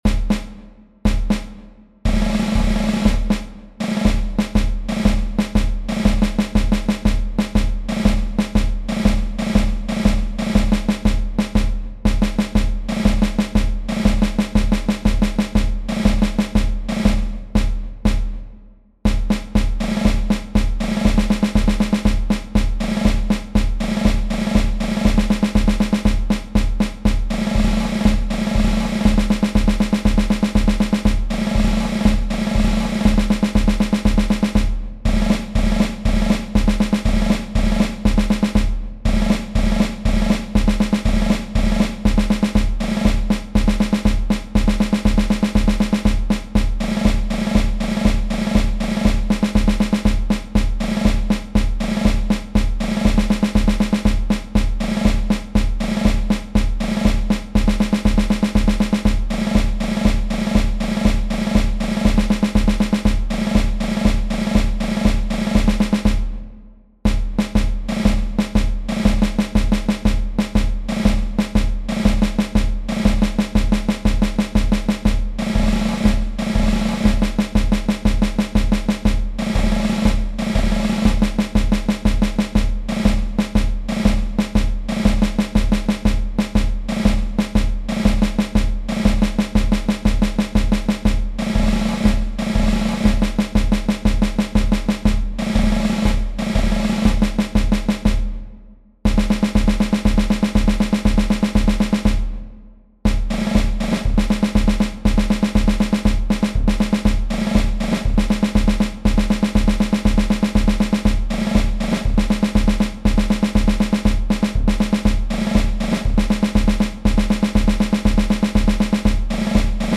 Synthesizer
snare drum
I just added a bass drum and cymbals.
percussion solo